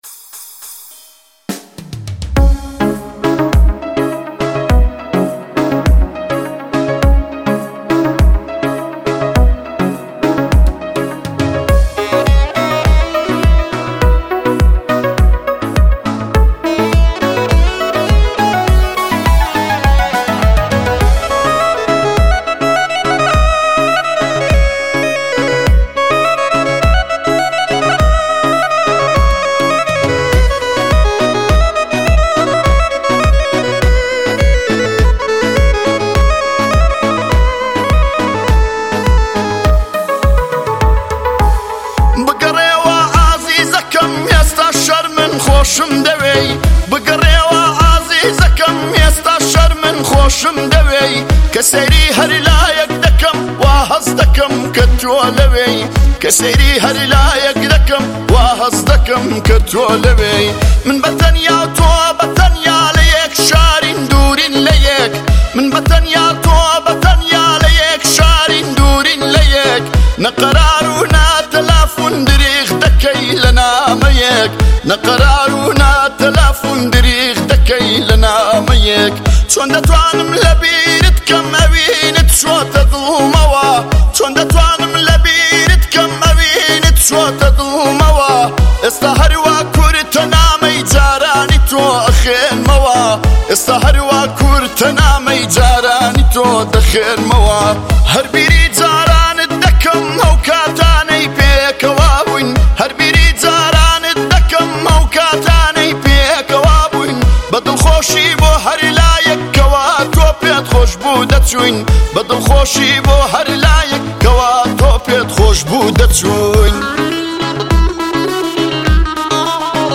Kurdish music
آهنگ کردی شاد